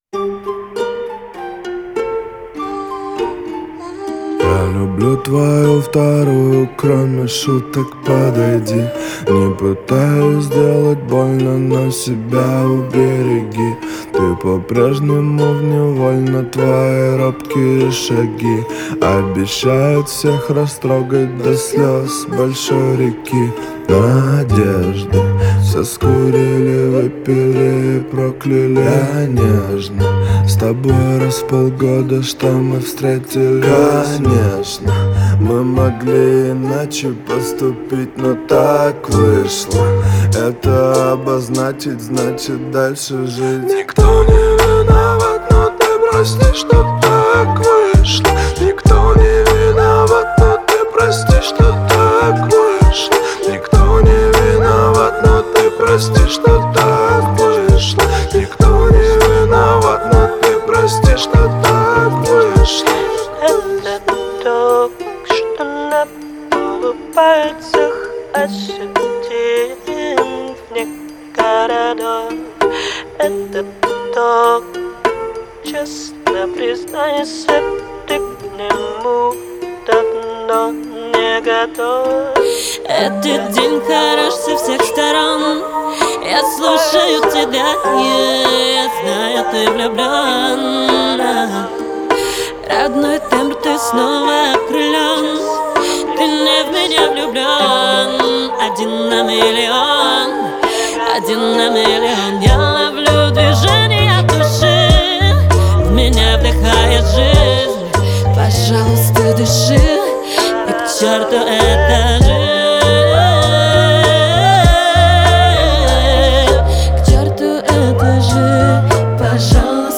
это трек в жанре современного поп и рэп, исполненный дуэтом